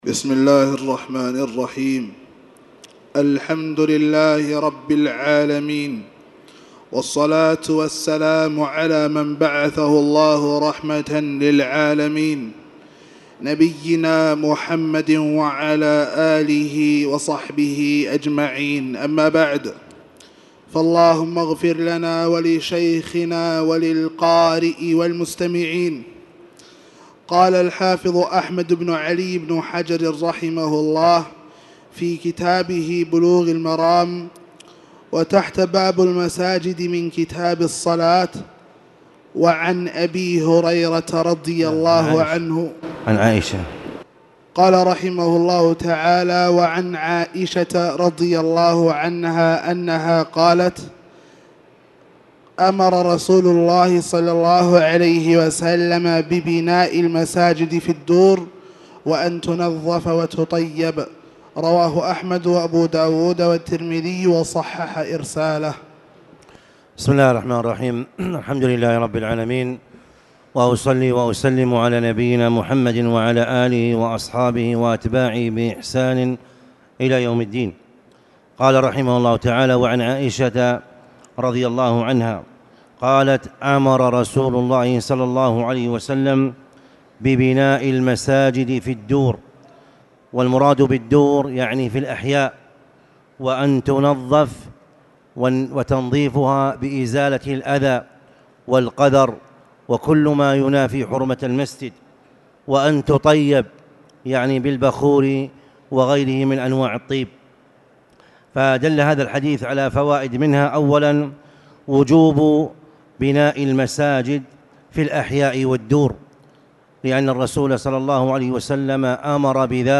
تاريخ النشر ٤ رجب ١٤٣٨ هـ المكان: المسجد الحرام الشيخ